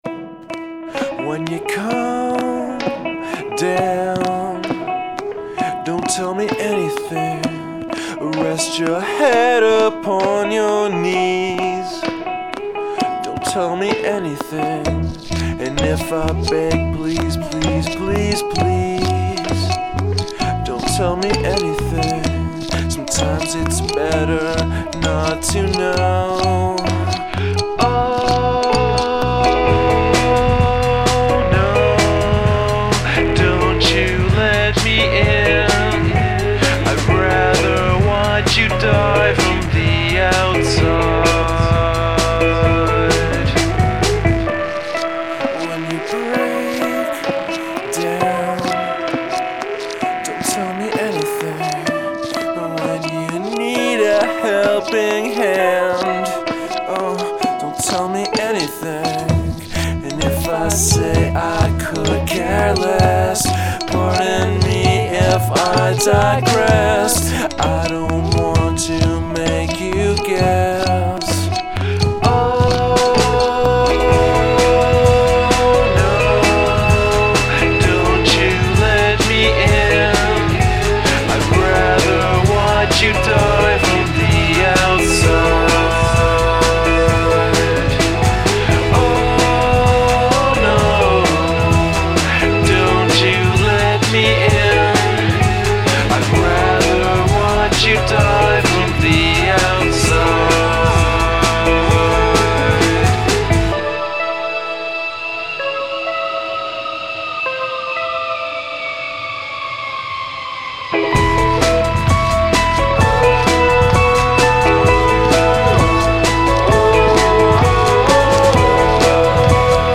Uncommon Percussion